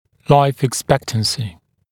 [laɪf ɪk’spektənsɪ][лайф ик’спэктэнси]ожидаемая продолжительность жизни